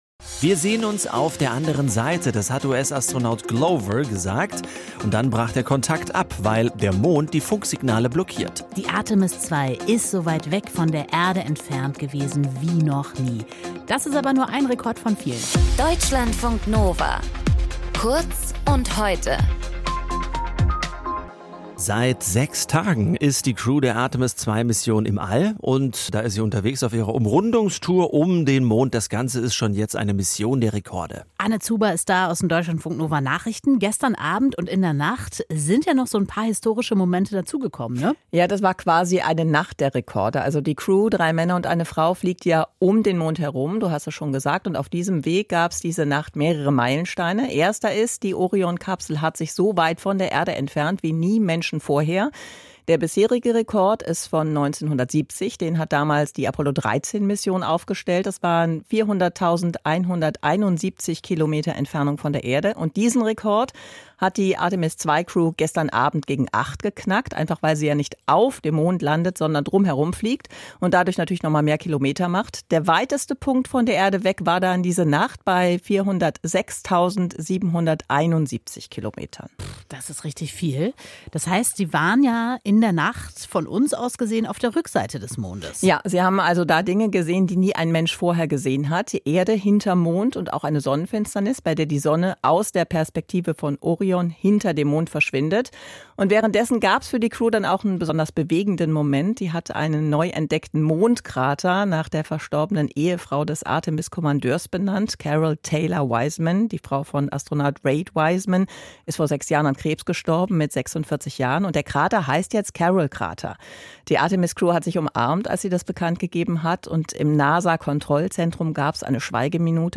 Moderation
Gesprächspartnerin